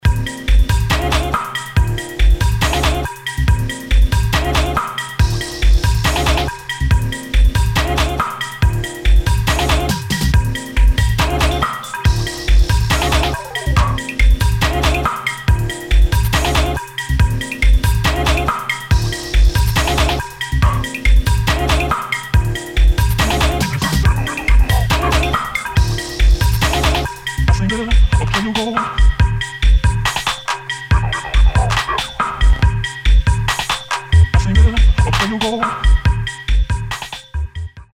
[ DOWNBEAT / BASS / EXPERIMENTAL ]